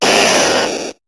Cri d' Arbok dans Pokémon HOME